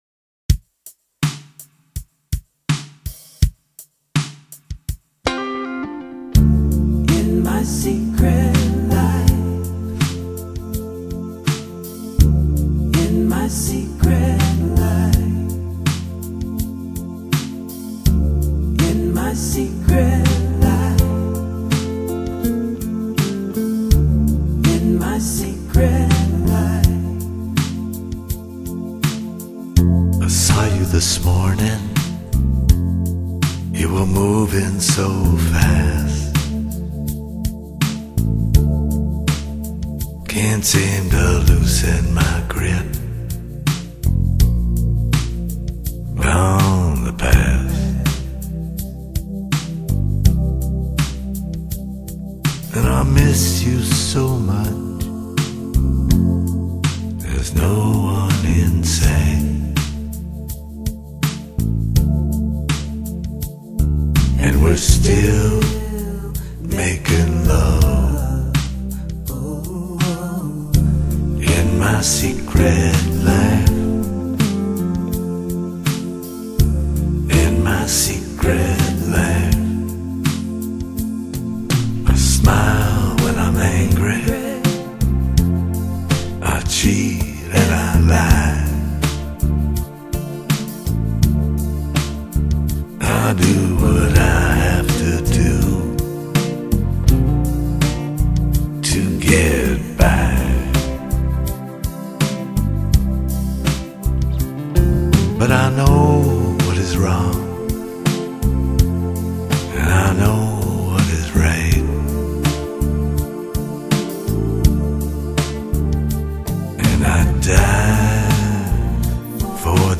【唱片风格】民谣/迷幻